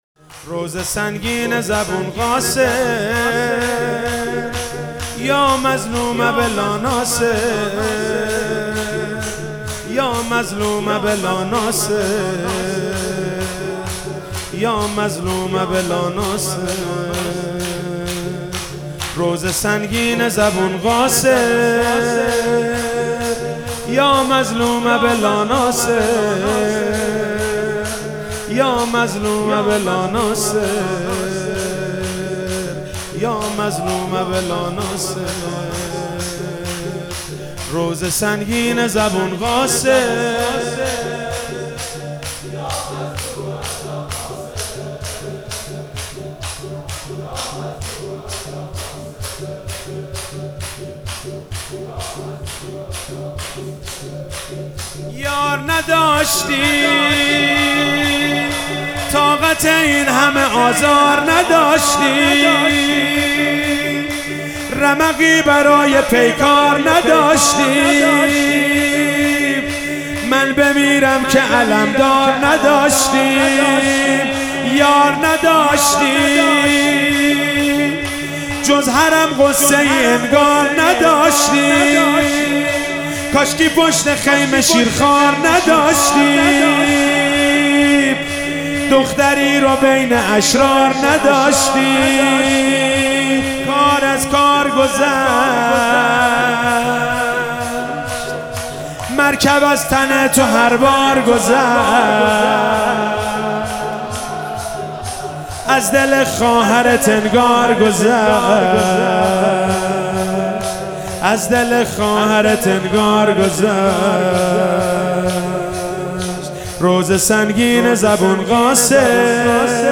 محرم 98 شب ششم - شور - روضه سنگینه زبون قاصر